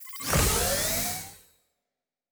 Sci-Fi Sounds / Doors and Portals
Door 10 Open.wav